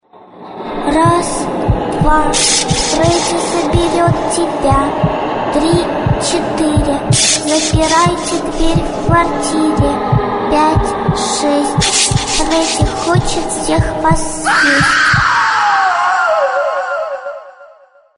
Главная » Файлы » Акапеллы » Скачать Русские акапеллы